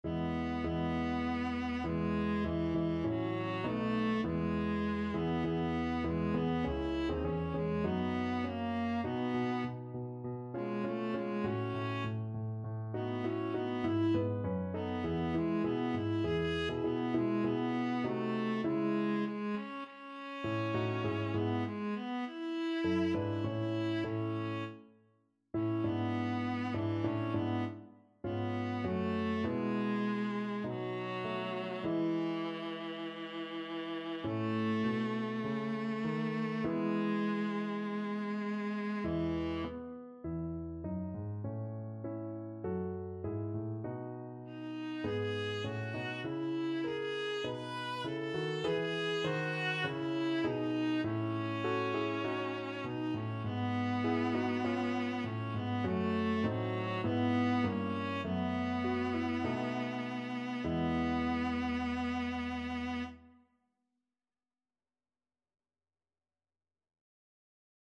Viola version
4/4 (View more 4/4 Music)
Classical (View more Classical Viola Music)